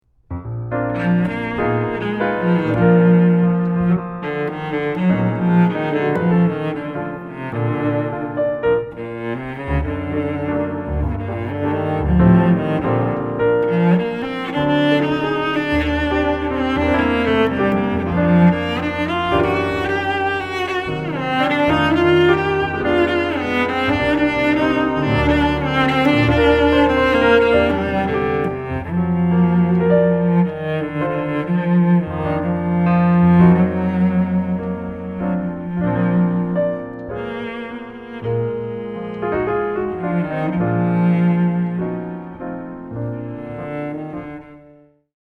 (piano)
(jazz waltz)
cello - viola